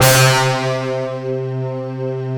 ABYSS C4.wav